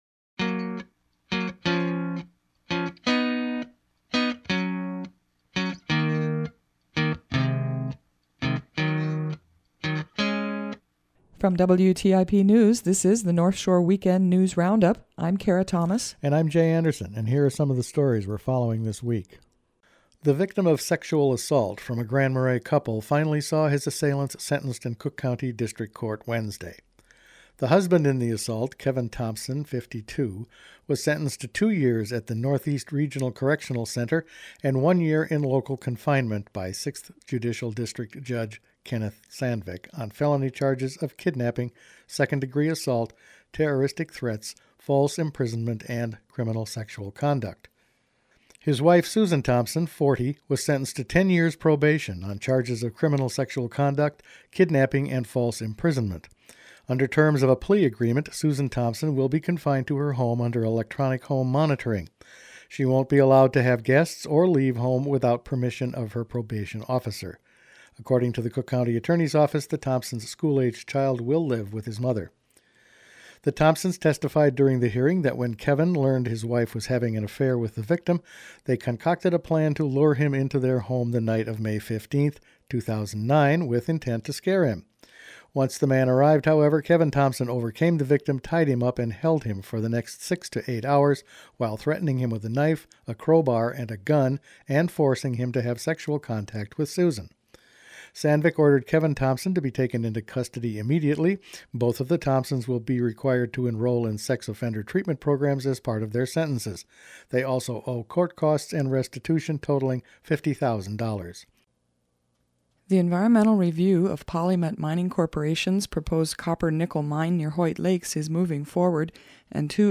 Each weekend the WTIP news department produces a wrap-up of the week’s news. More on broadband, news on the PolyMet mine project, Local Government Aid woes and more in this report.